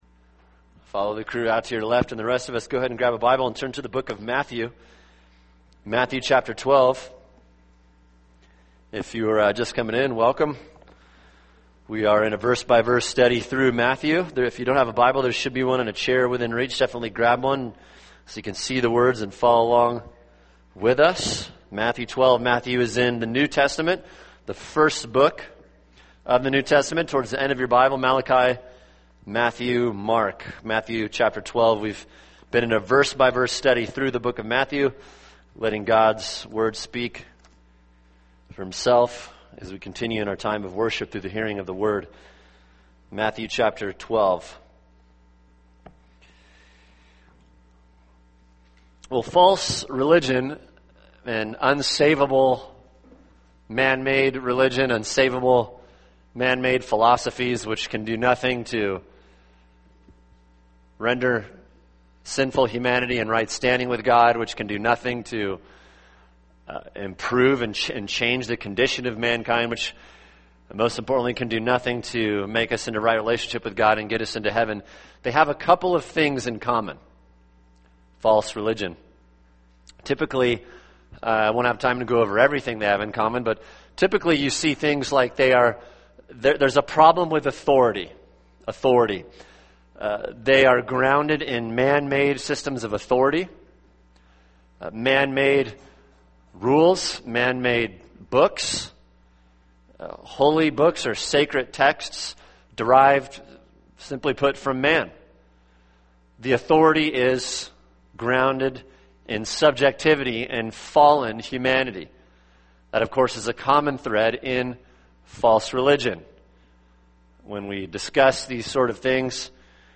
[sermon] Matthew 12:1-8 – Something Greater Than the Temple | Cornerstone Church - Jackson Hole